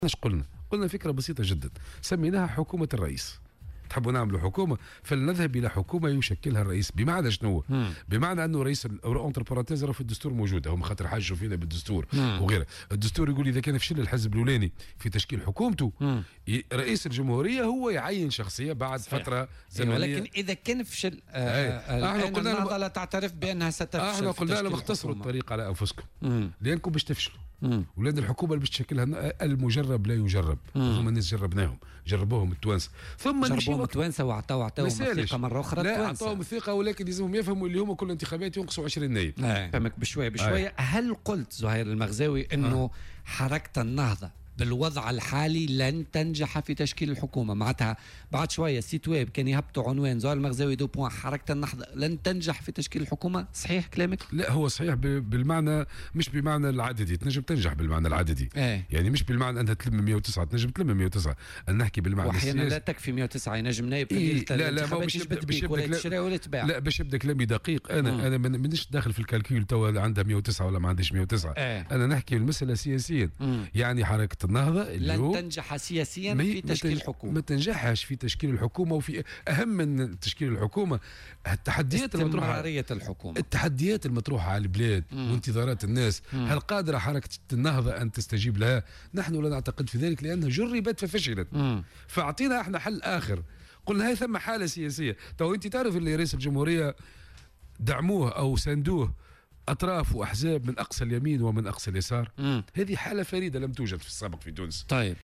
وأضاف المغزاوي، ضيف برنامج "بوليتيكا" اليوم الاثنين أن حركة النهضة "جُرّبت ولكنها فشلت".